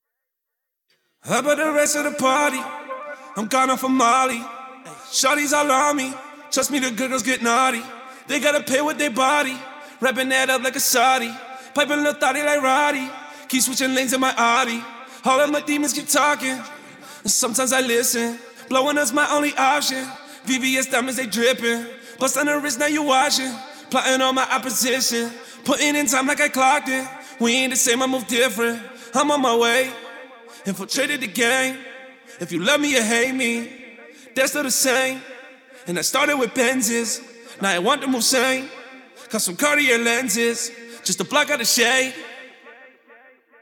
[NO ADLIBS] POP SMTHN RAP VERSE 138 BPM.wav